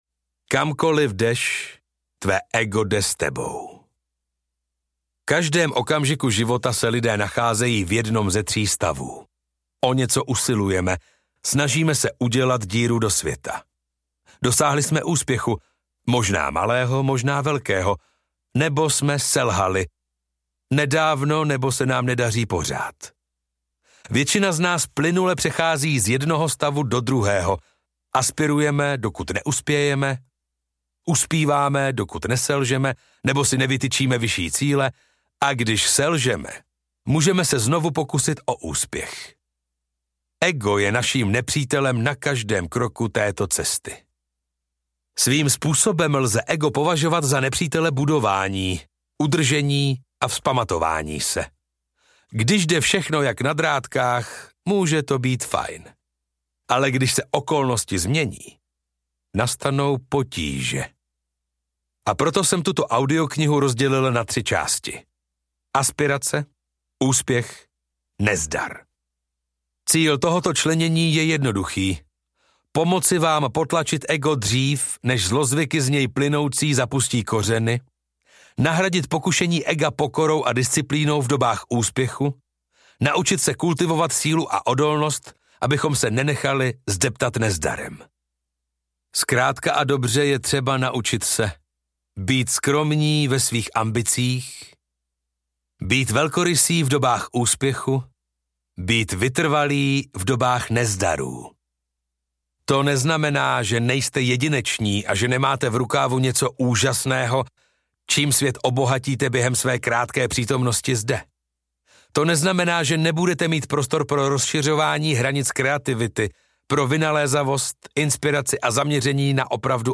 Ego je nepřítel audiokniha
Ukázka z knihy